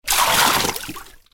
دانلود آهنگ آب 38 از افکت صوتی طبیعت و محیط
دانلود صدای آب 38 از ساعد نیوز با لینک مستقیم و کیفیت بالا
جلوه های صوتی